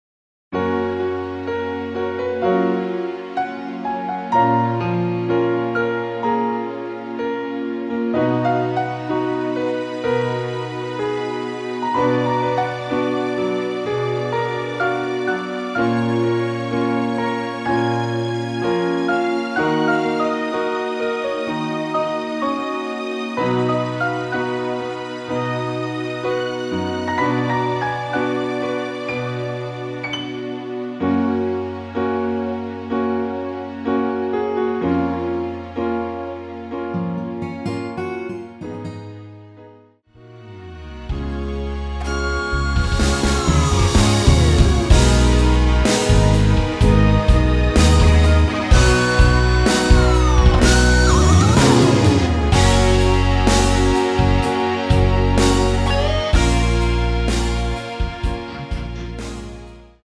MR 입니다.
F#
앞부분30초, 뒷부분30초씩 편집해서 올려 드리고 있습니다.
중간에 음이 끈어지고 다시 나오는 이유는